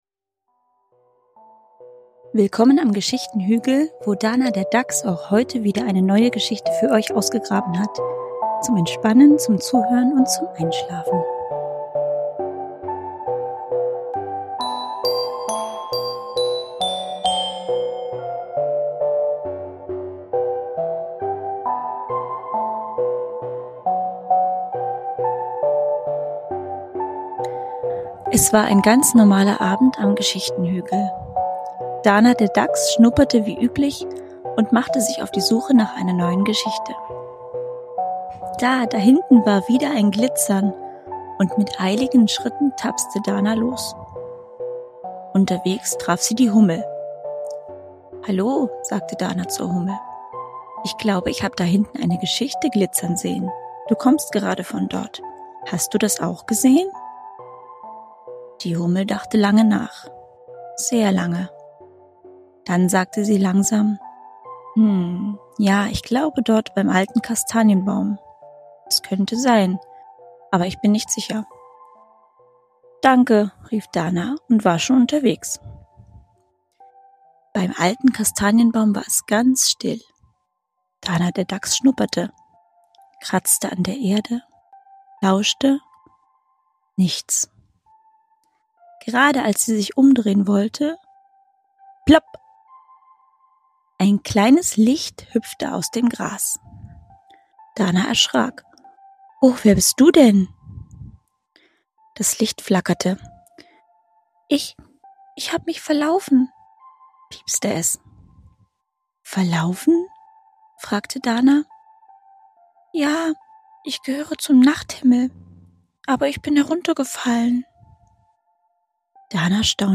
Geschichten für Kinder vom Geschichtenhügl
Ruhige Geschichten für Kinder – zum Entspannen, Zuhören und Einschlafen.